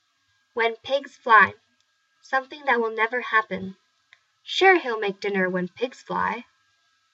英語ネイティブによる発音は以下のリンクをクリックしてください。